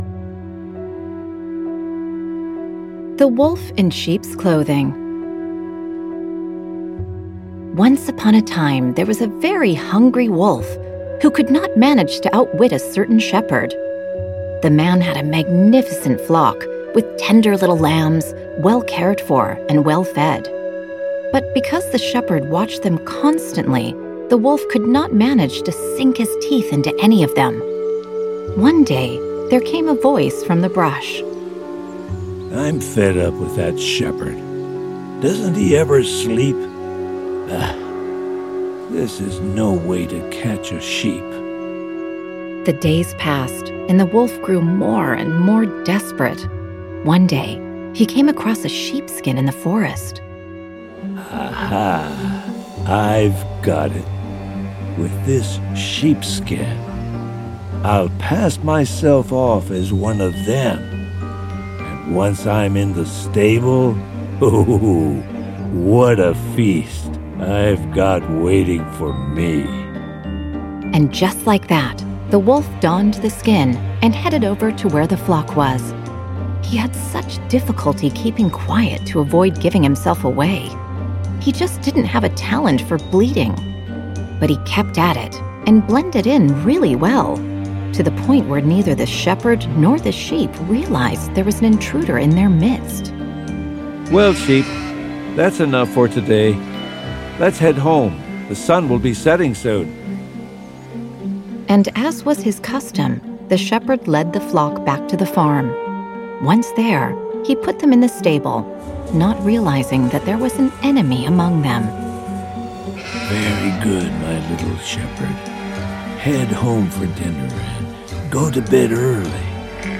Una selección de 25 fábulas de Esopo adaptadas a niños de 6 años en formato audiolibro. Los niños escucharán las fábulas de siempre, narradas con voces de cine, música y efectos especiales.